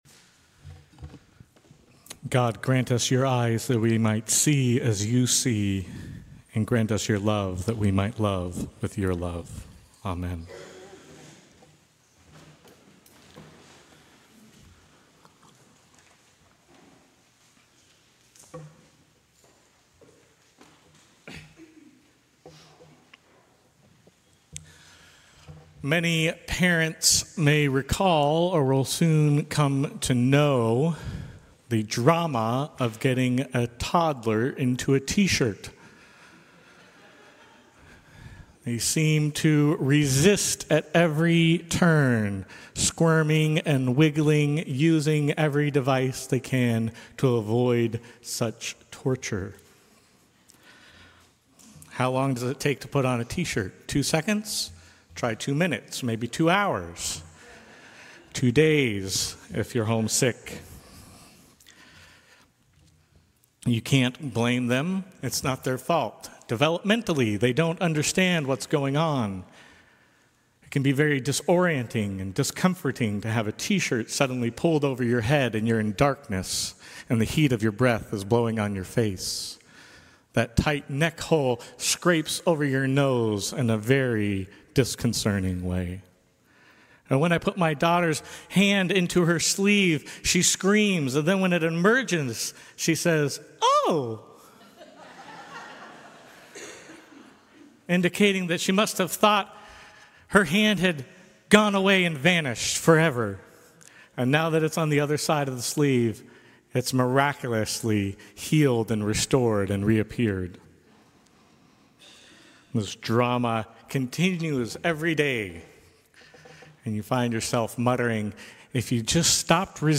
Sermons from St. Cross Episcopal Church Fourth Sunday in Lent Jan 08 2024 | 00:12:55 1x 00:00 / 00:12:55 Subscribe Share Apple Podcasts Spotify Overcast RSS Feed Share Link Embed